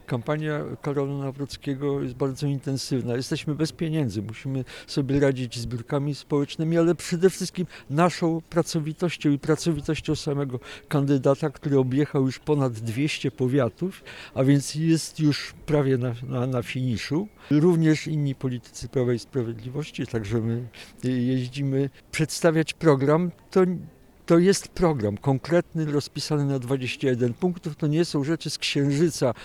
O kampanii prowadzonej przez Karola Nawrockiego mówi Jacek Świat – poseł na Sejm.